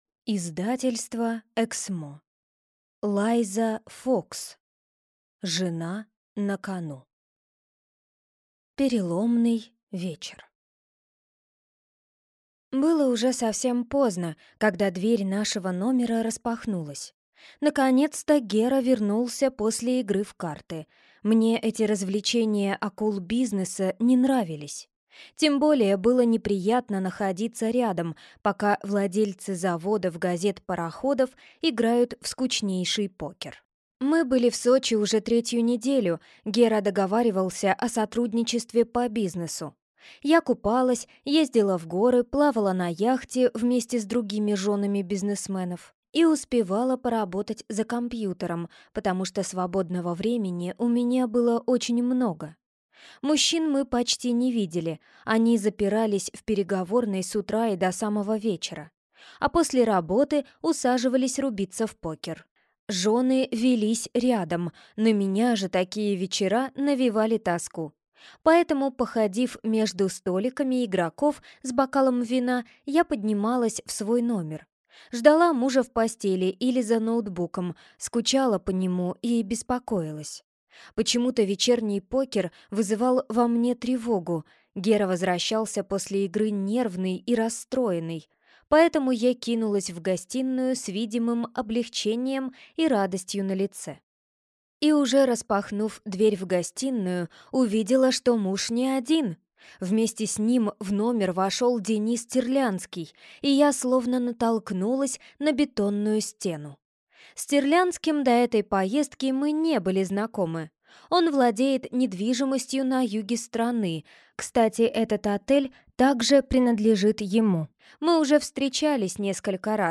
Валсарб (слушать аудиокнигу бесплатно) - автор Хелена Побяржина